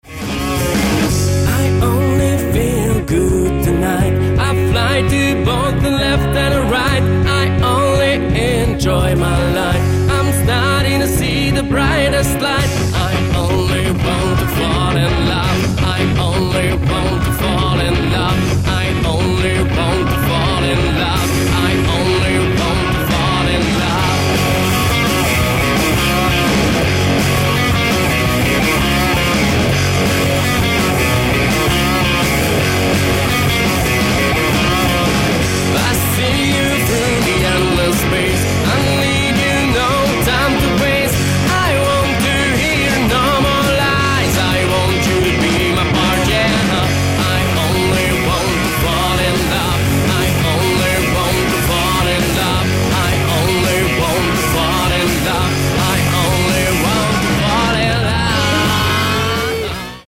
Solo Gitár
Baszus gitár
DEMO